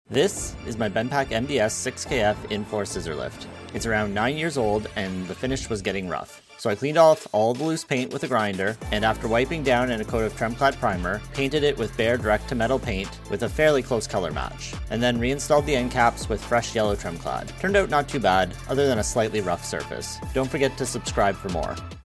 Car Lift - Bendpak MDS-6KF Scissor Lift